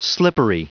Prononciation du mot slippery en anglais (fichier audio)
Prononciation du mot : slippery